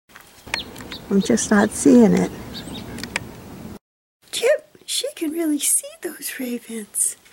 Throughout much of the video there will be a clip of Songbird speak followed by my attempt to imitate the Songbird accent while speaking what seem to me to be the words they just spoke.